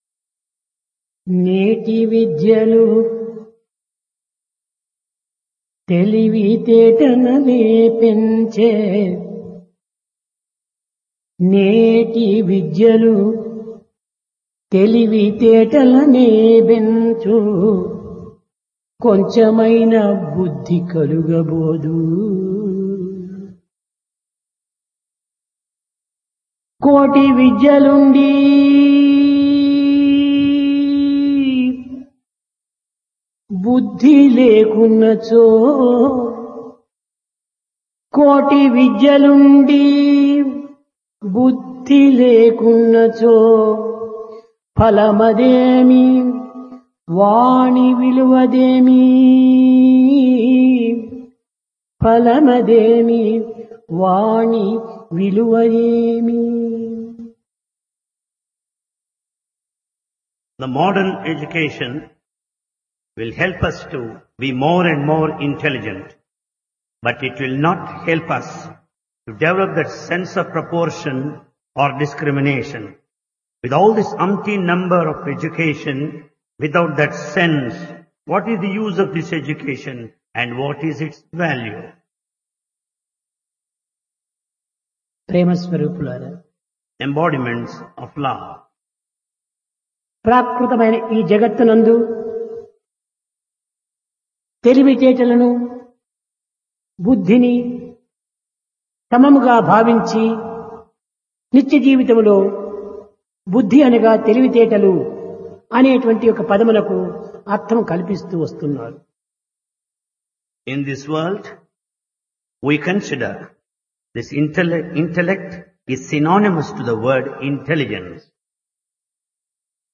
Discourse
Place Prasanthi Nilayam